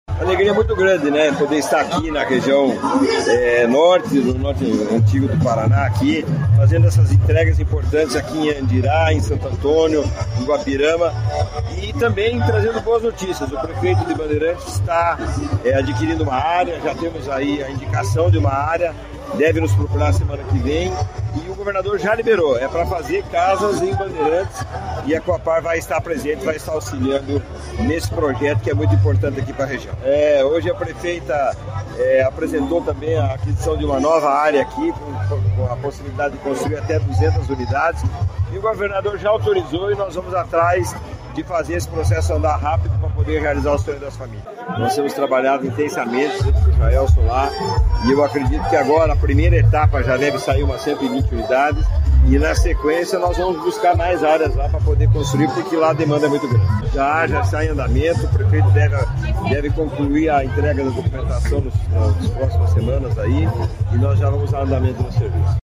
Sonora do presidente da Cohapar, Jorge Lange, sobre a entrega de residencial com 134 casas em Andirá